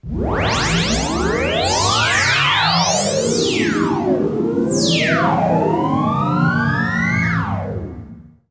LASER ARMY.wav